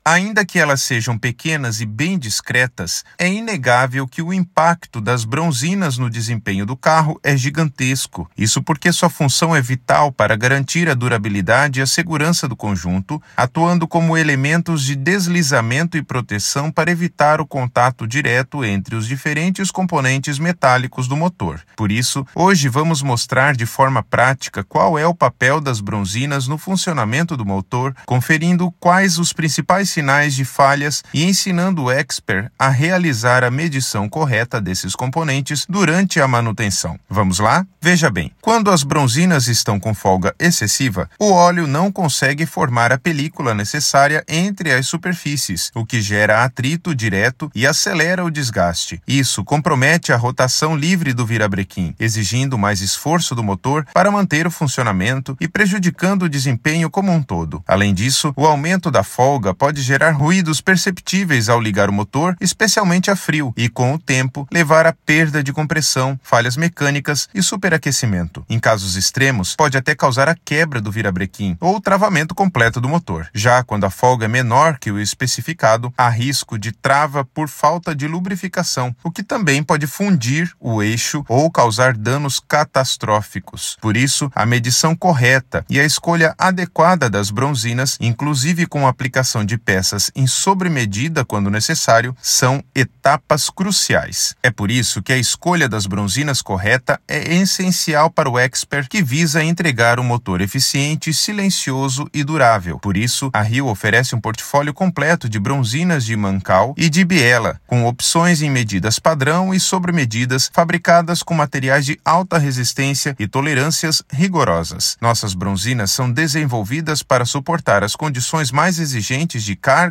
Narracao-02-impacto-das-bronzinas.mp3